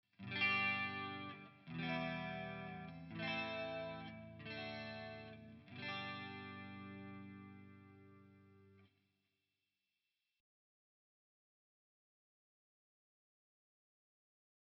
Gmaj – Emin – Amin – Dmaj
This turnaround (sometimes called “harmonic circle”) is basically a I-vi-ii-V in the key of G major.